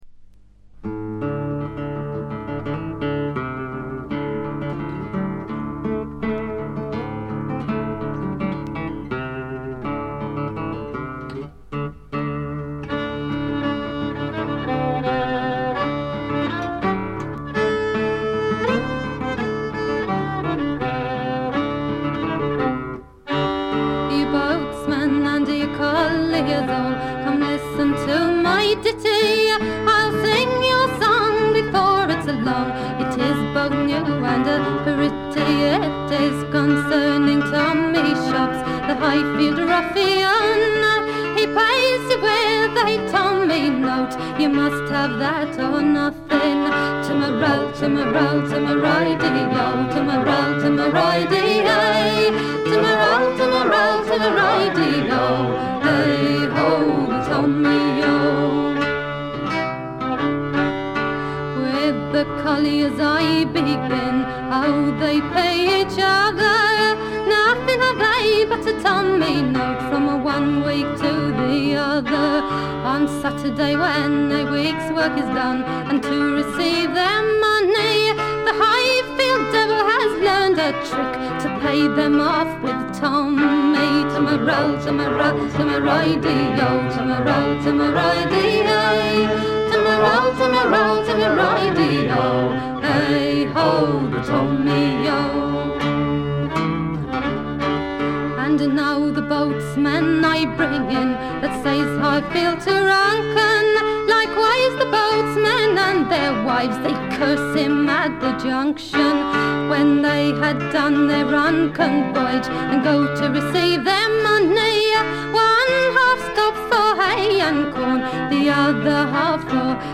レア度、内容ともに絶品のフィメールトラッドフォークです。
試聴曲は現品からの取り込み音源です。